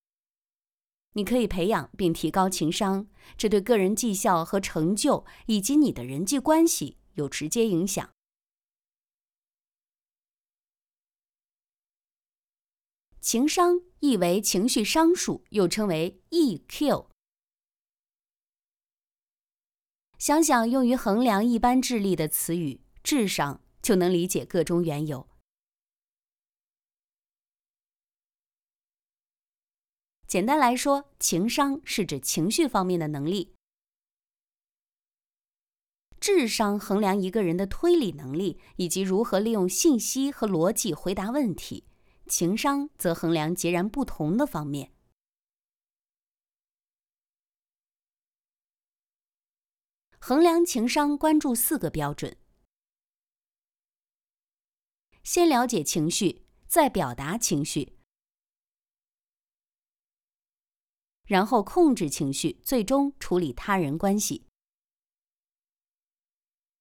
Chinese_Female_041VoiceArtist_2Hours_High_Quality_Voice_Dataset